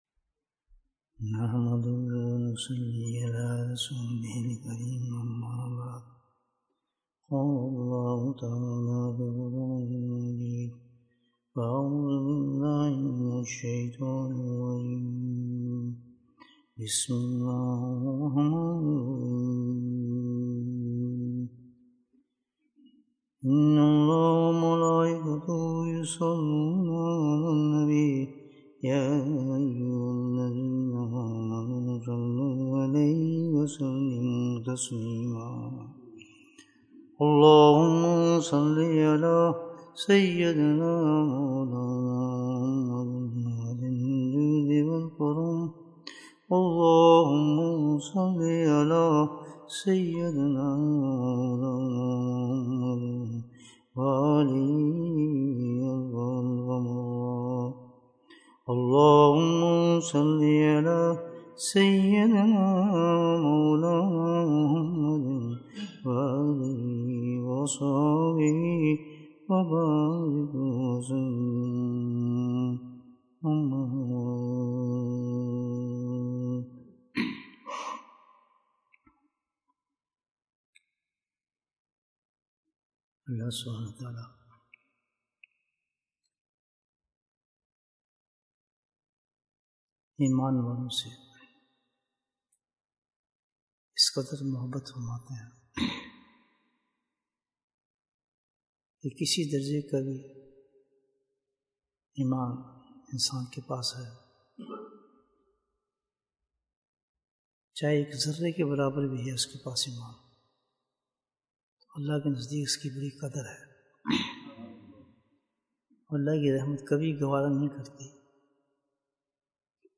Bayan, 48 minutes 19th January, 2023 Click for English Download Audio Comments How Should We Celebrate Jumu'ah?